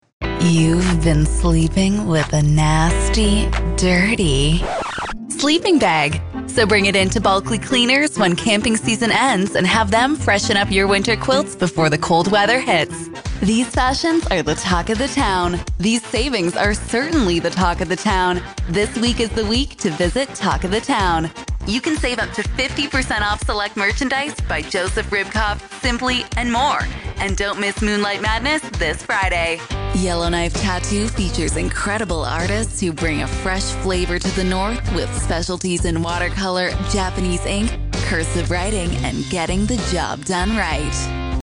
Anglais Canadien
SHOWREEL